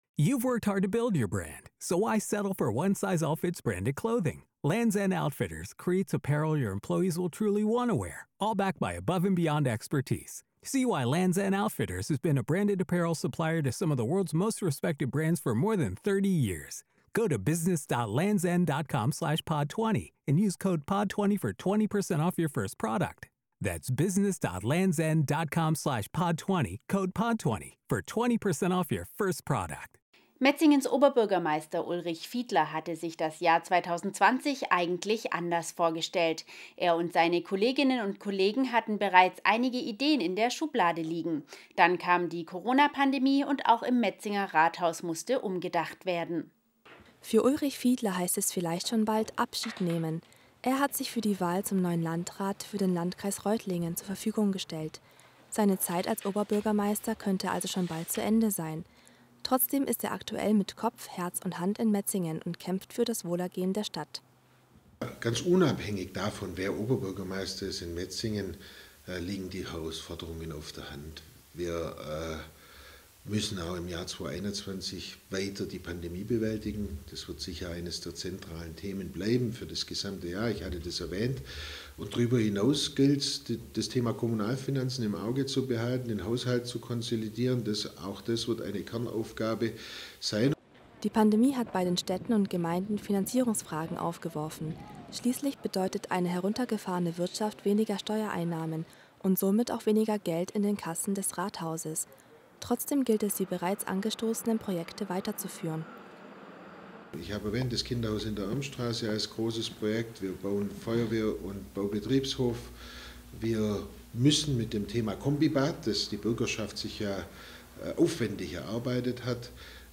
Rückblick 2020 & Ausblick: Metzingen | Interview mit OB Ulrich Fiedler